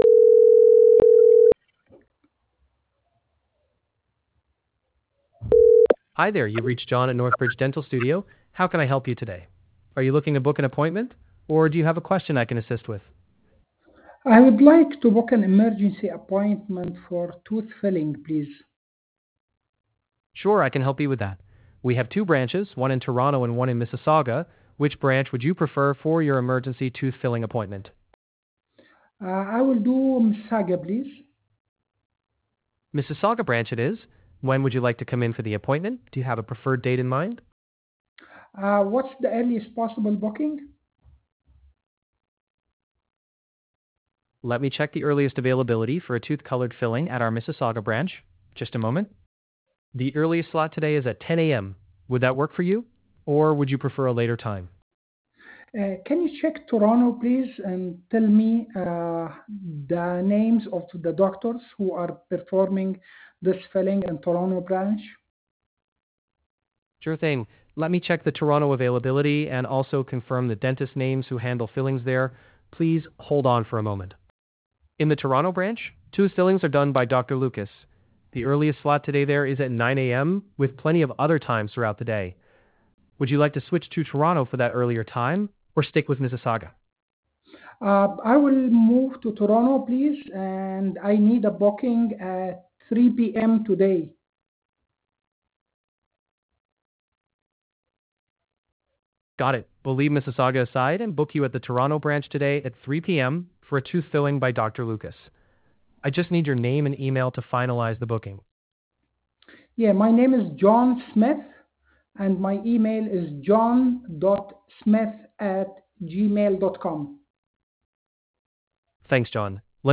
AI Real Call
Two minute s • Dental Clinic booking in English
Dental-booking-English.wav